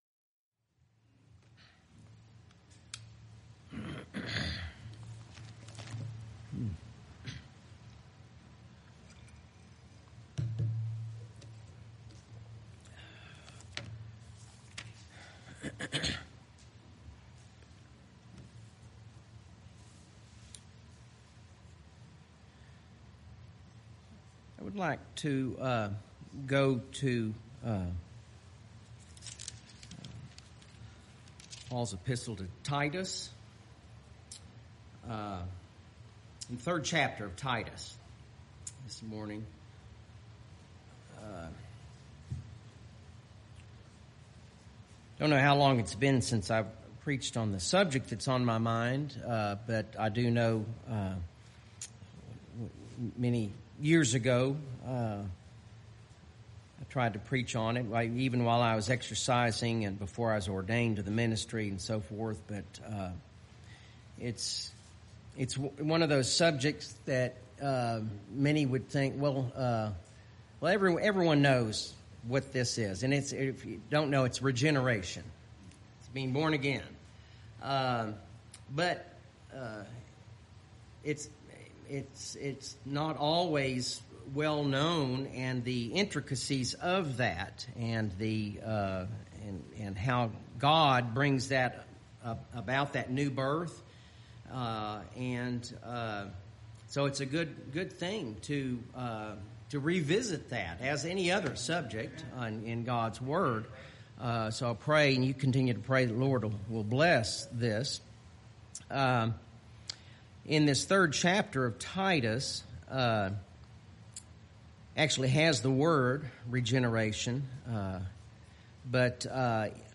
BHPBC Sermon podcast